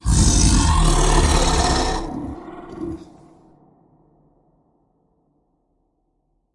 幻想 " 龙咆哮04 ( 关闭 )
标签： 恐龙 怒吼 可怕 咆哮 野兽 巨人 恐怖 到目前为止 中世纪 生物 WAV 动物 森林 咆哮 怪物 Z ombie 关闭 遥远的 地牢 咆哮
声道立体声